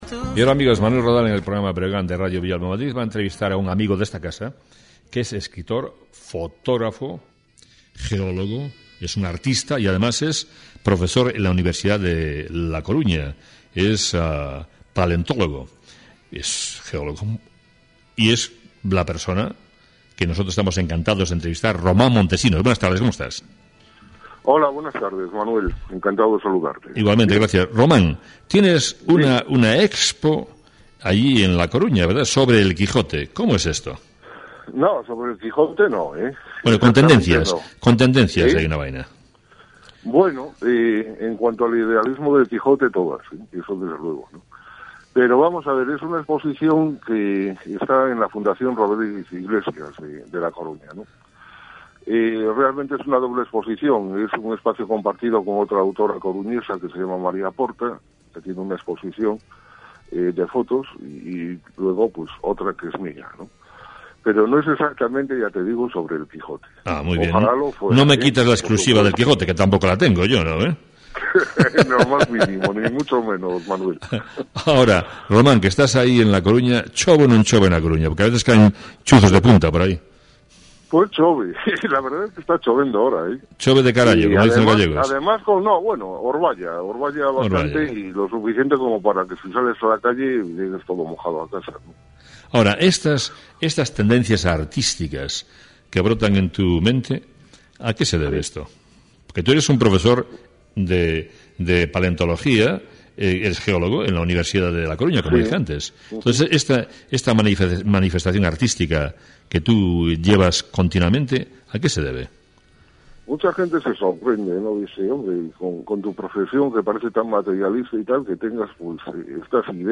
AUDIO: Donde digo Diego dije digo… Entrevista de radio.